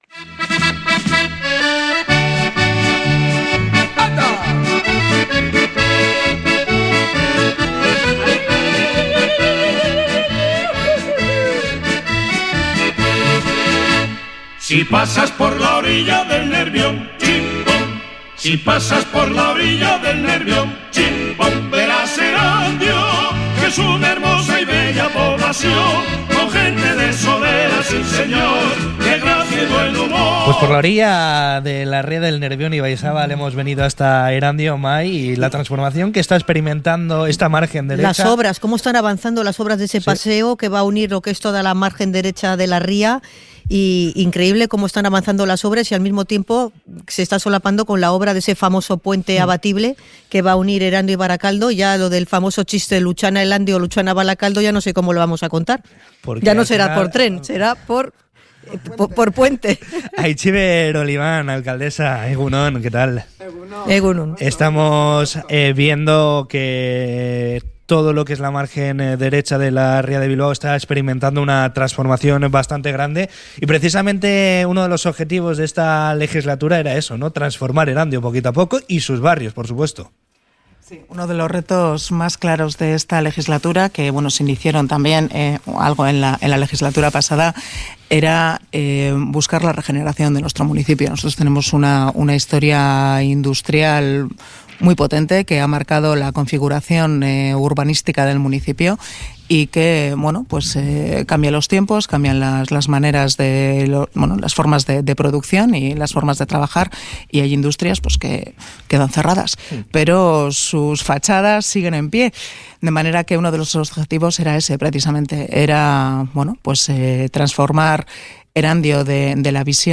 Entrevista con la alcaldesa de Erandio, Aitziber Oliban en el Merkatu Zaharra